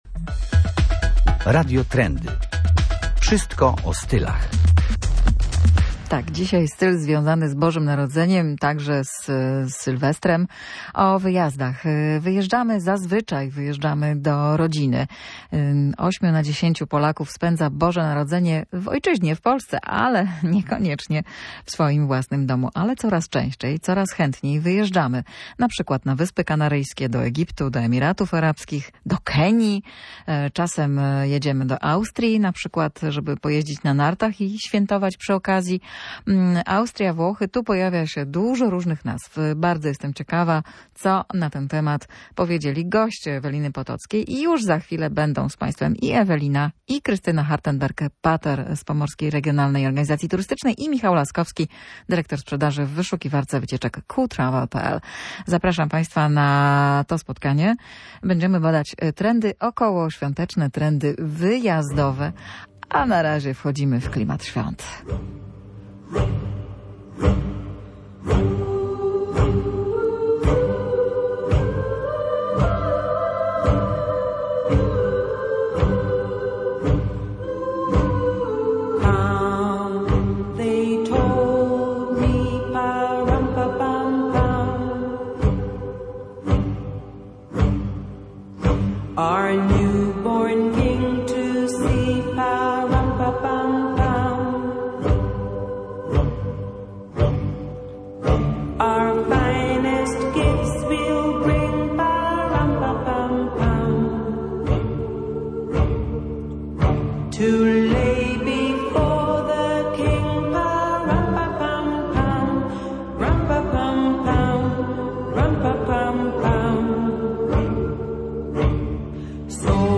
Ile kosztują takie wczasy? Ile Polacy wydają na tego typu wycieczki? Czego szukają podczas takich wyjazdów? O tym wszystkim rozmawiamy w audycji Radio Trendy.